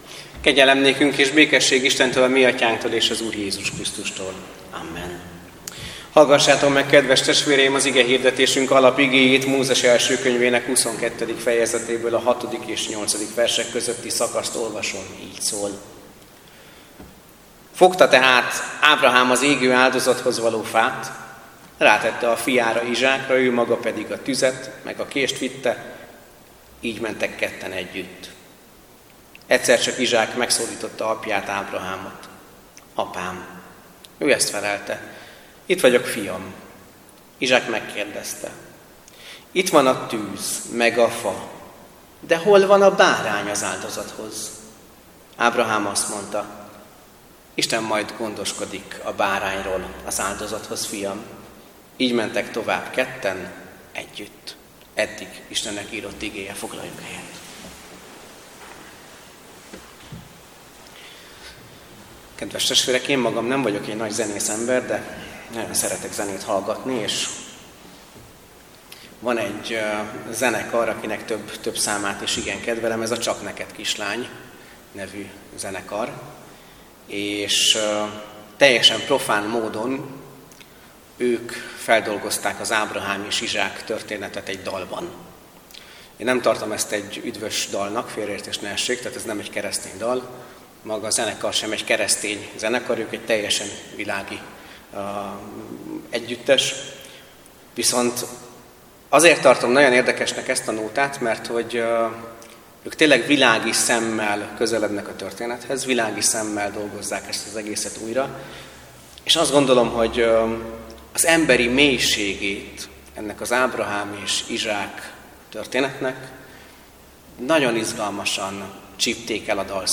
Böjt esti áhítat - Áldott az Isten, a mi Urunk Jézus Krisztus Atyja, az irgalom Atyja és minden vigasztalás Istene.